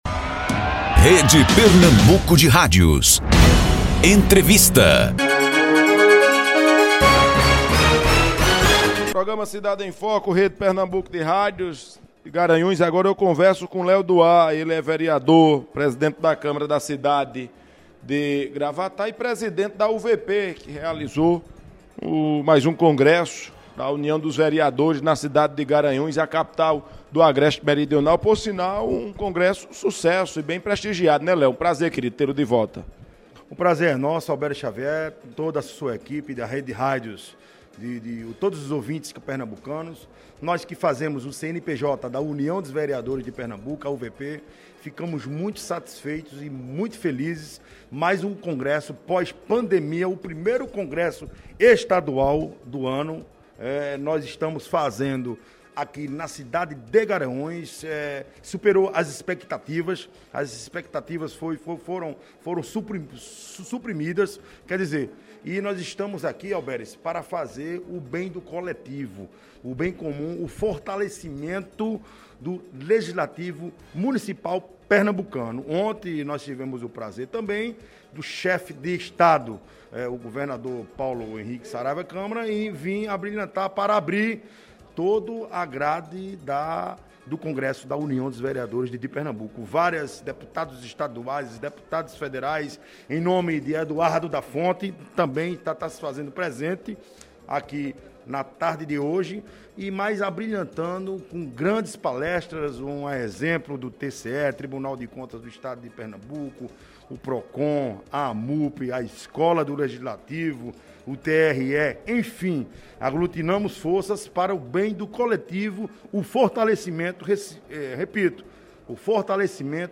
Em entrevista ao programa Cidade em Foco, da Rede Pernambuco de Rádios, Léo afirmou que os números superaram as expectativas da organização da UVP.